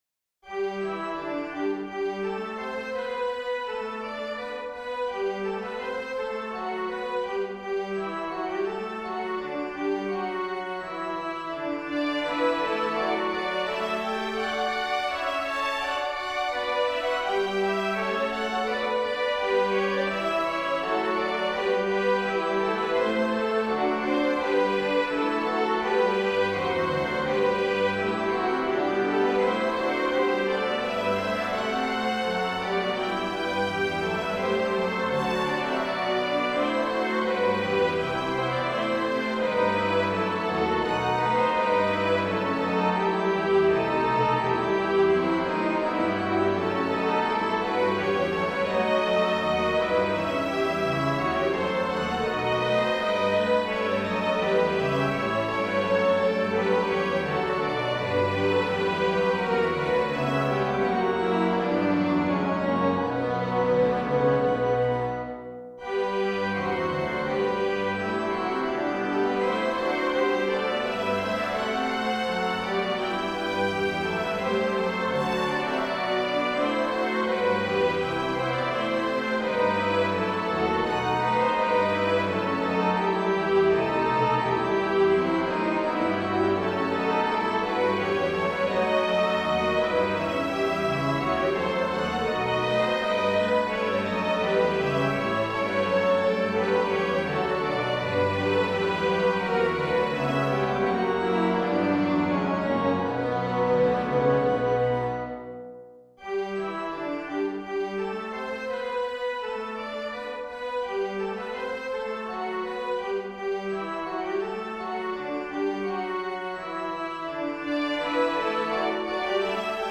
set for Strings, Organ and Bass Trombone